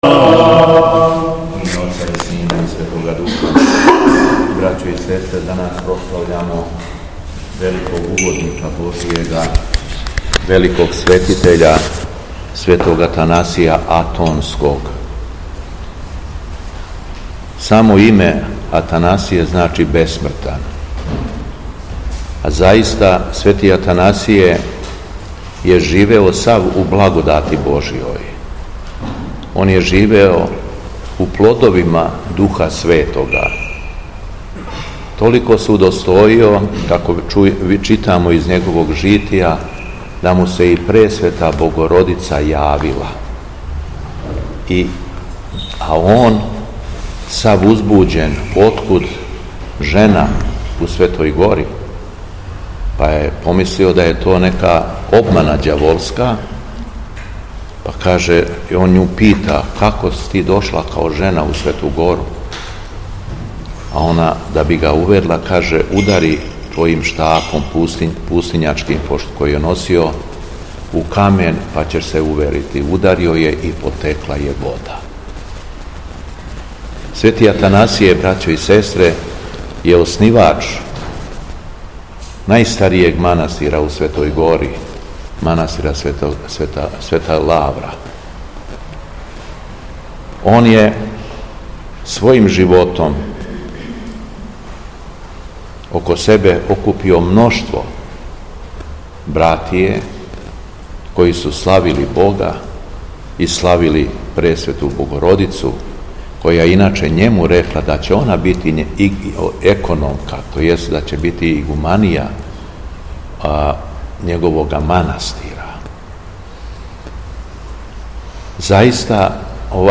ЛИТУРГИЈСКО САБРАЊЕ У СТАРОЈ ЦРКВИ У КРАГУЈЕВЦУ - Епархија Шумадијска
Беседа Његовог Високопреосвештенства Митрополита шумадијског г. Јована
Беседећи верном народу Митрополит Јован је рекао: